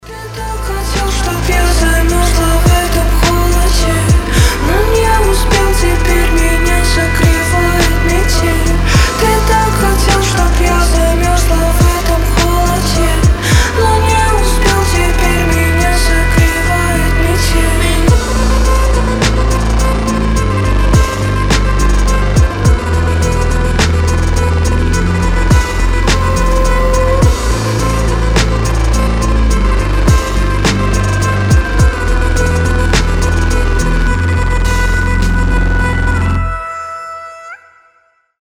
грустные
атмосферные
мощные басы
Chill Trap
фолк
alternative
холодные